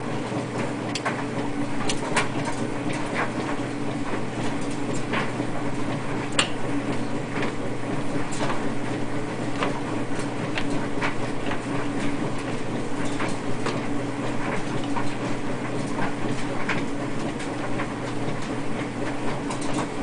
洗衣机 烘干机1
描述：洗衣机和干衣机都在运行。可循环使用。
Tag: 环境 - 声音的研究 衣服烘干机 洗衣店 洗衣房 洗衣机